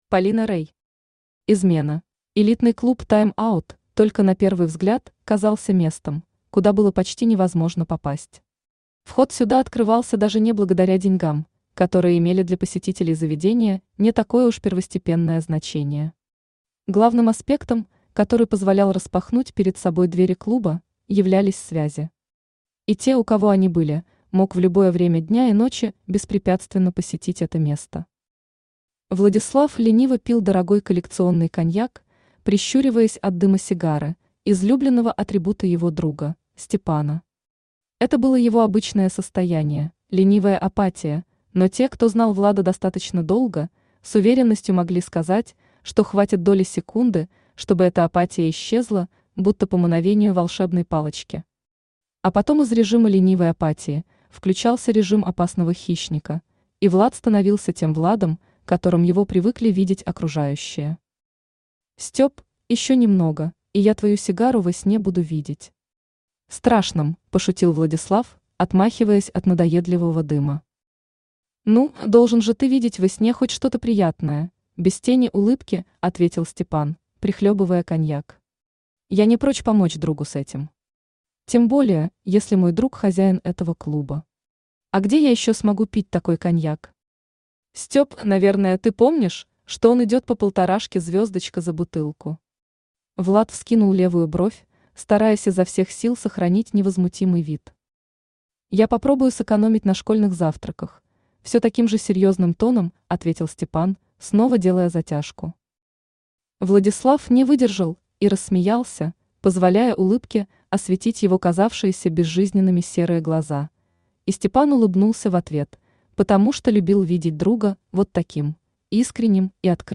Aудиокнига Измена Автор Полина Рей Читает аудиокнигу Авточтец ЛитРес.